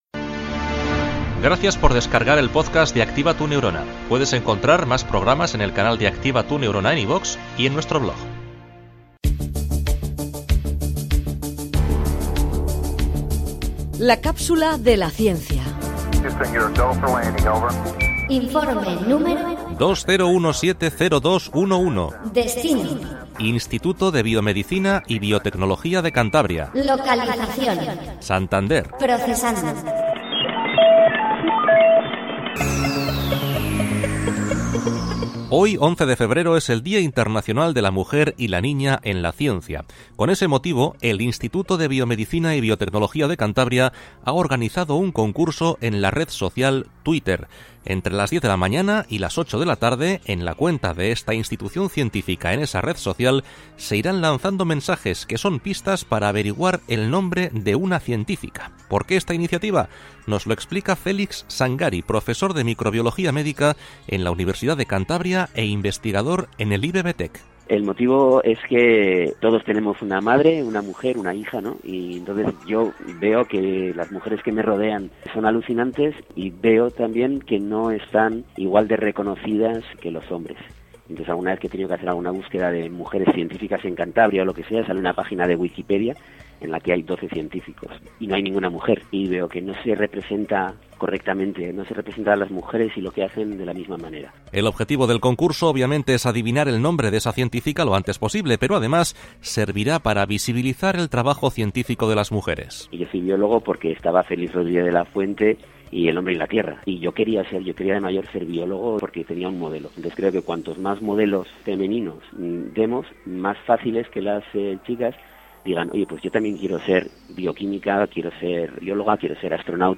La comunicación de la ciencia es la protagonista de 3 minutos de radio en los que colaboramos con científicos punteros para contar de forma amena y sencilla los resultados de sus últimas investigaciones.